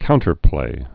(kountər-plā)